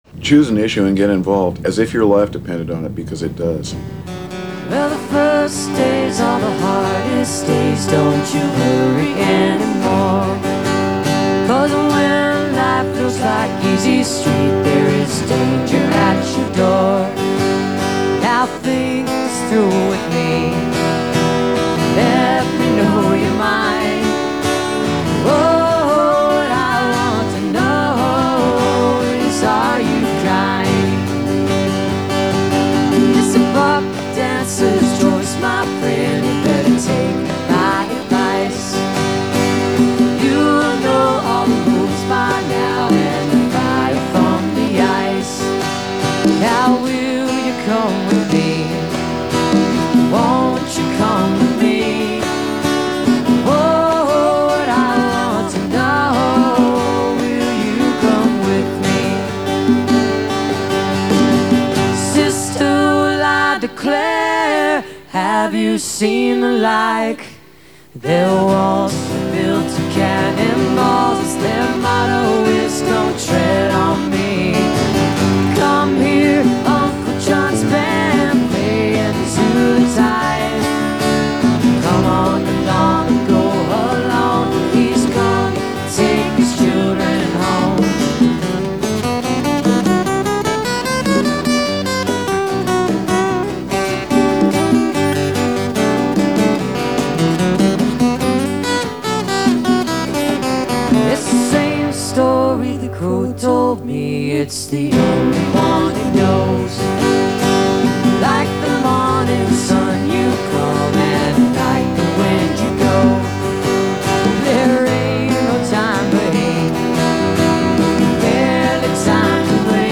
fades out